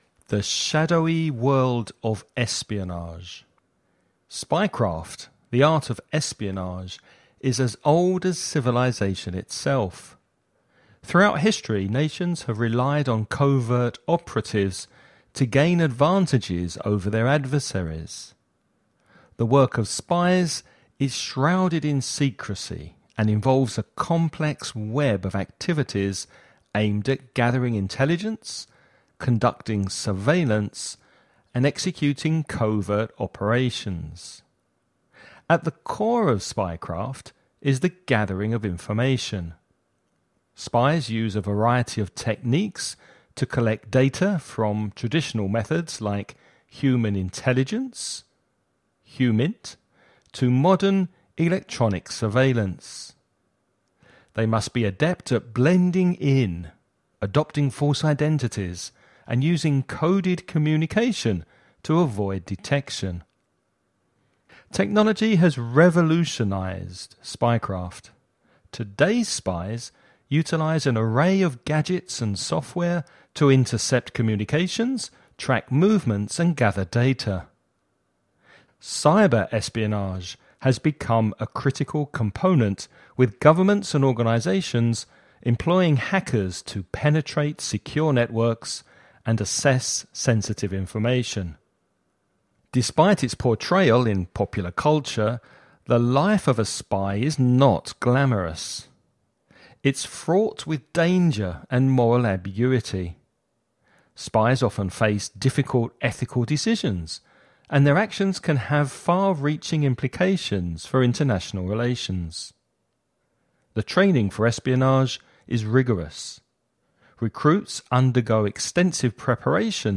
You’re going to listen to a man talking about espionage and spying.